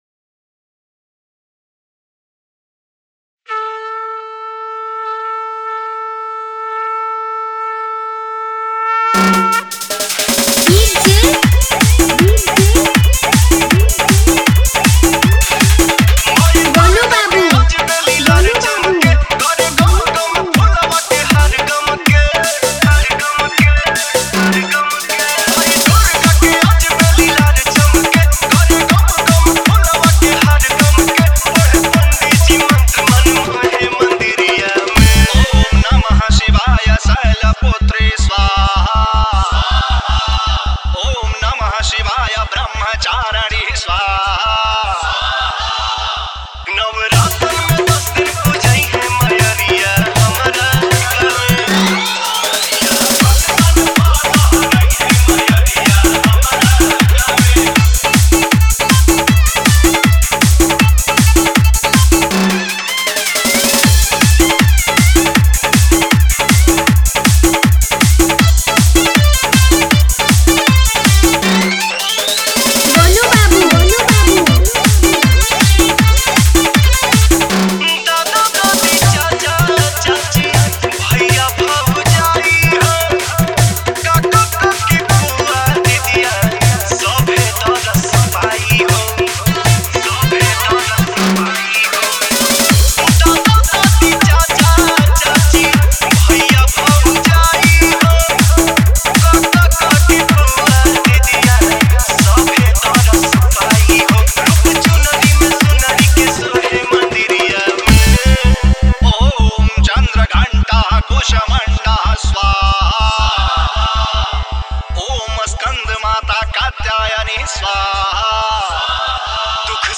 Bhakti Dj Songs